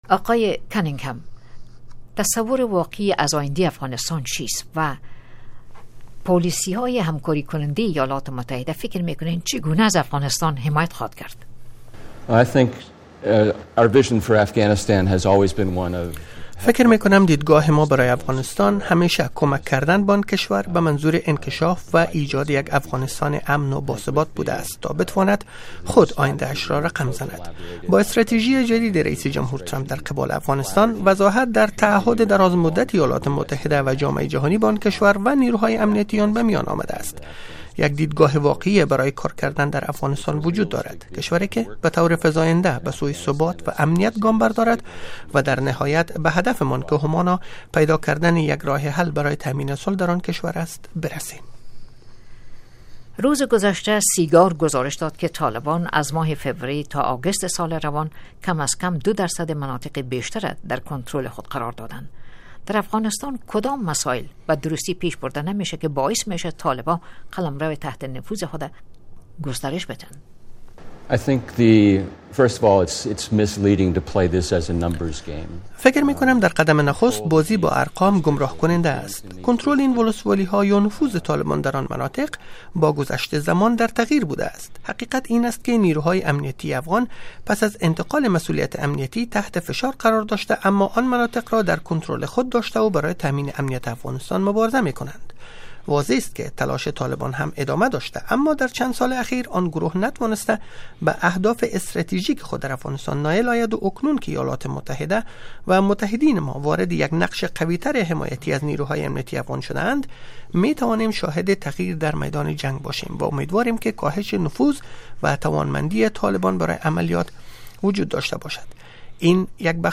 مصاحبه ها
جیمز کننگهم، سفیر پیشین ایالات متحده در افغانستان